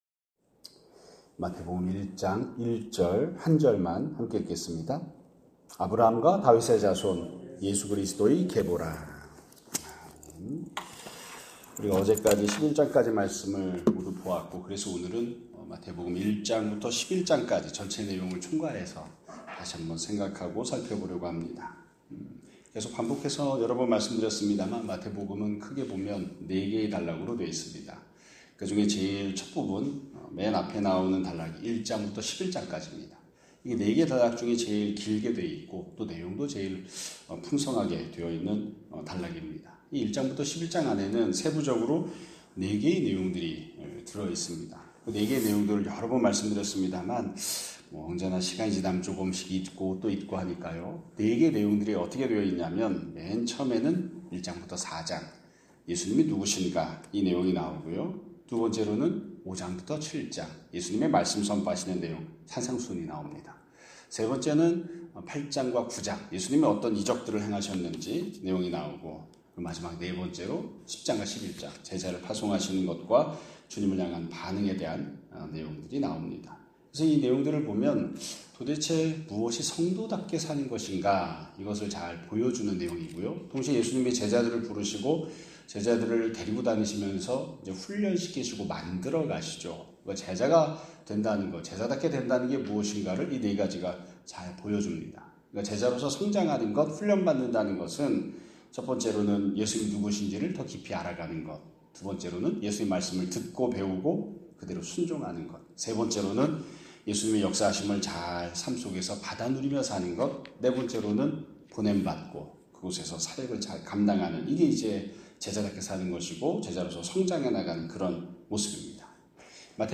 2025년 9월 4일 (목요일) <아침예배> 설교입니다.